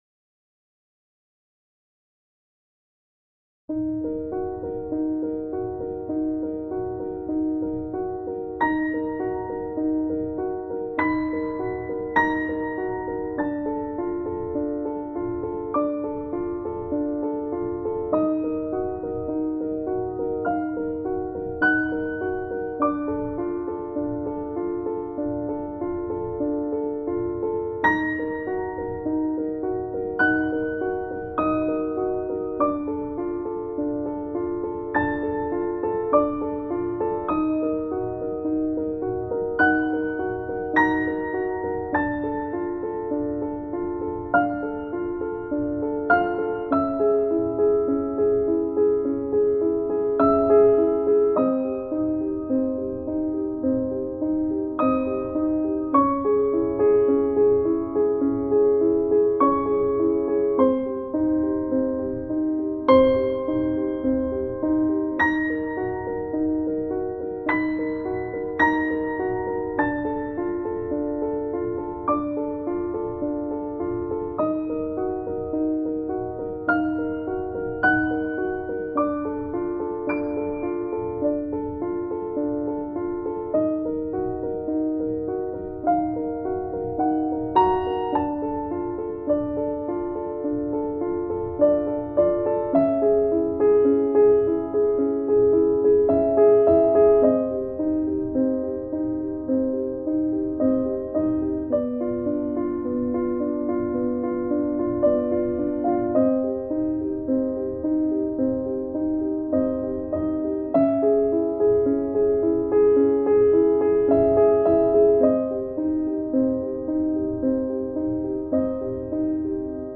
Dark-Piano-Music---Severndroog-Original-Composition-320-kbps-.mp3